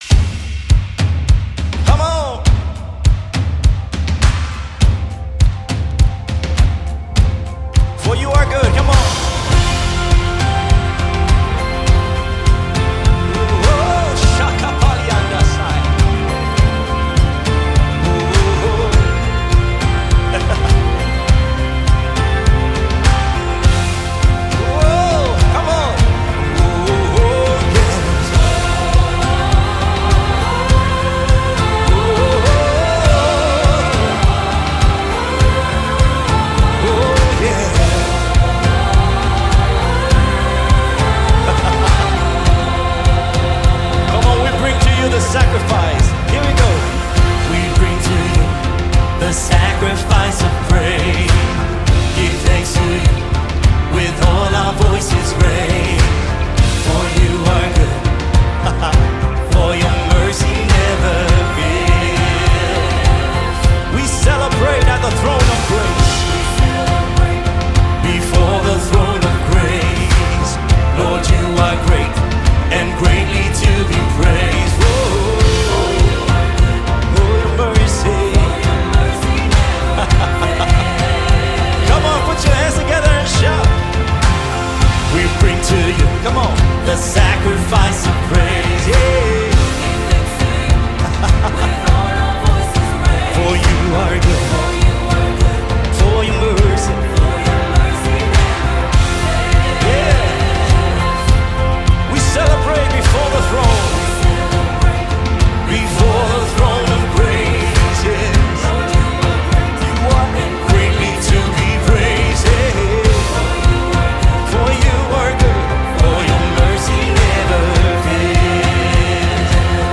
March 19, 2025 Publisher 01 Gospel 0